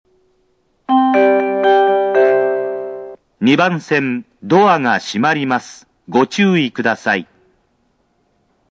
接近用と発車用とではスピーカーが大半異なっていますが、バスレフとの相性は悪く音割れ気味です。
＜スピーカー＞ 接近放送用：TOAバスレフ白　発車メロディー用：TOA小
発車メロディー+放送・男性   東武C PCM